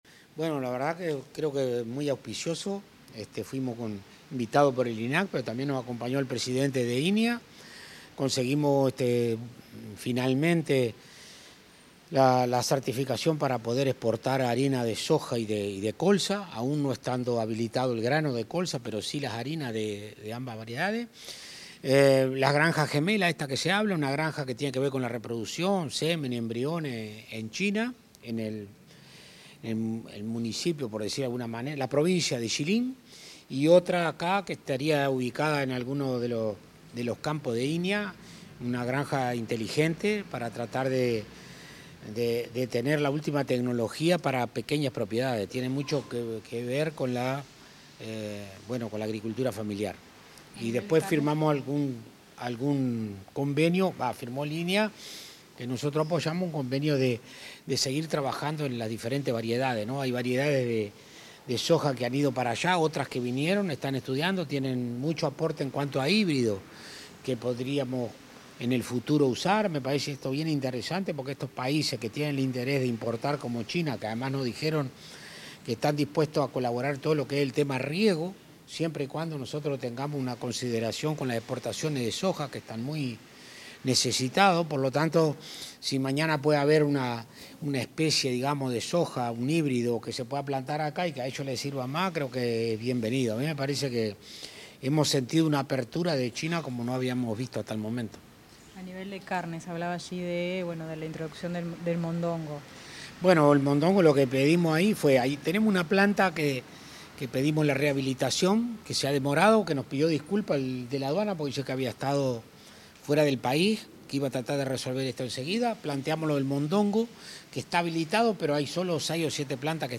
Declaraciones del ministro de Ganadería, Alfredo Fratti
Declaraciones del ministro de Ganadería, Alfredo Fratti 27/05/2025 Compartir Facebook X Copiar enlace WhatsApp LinkedIn El ministro de Ganadería, Alfredo Fratti, dialogó con los medios de prensa acerca de los resultados de una misión oficial en la República Popular China.